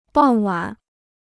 傍晚 (bàng wǎn) Free Chinese Dictionary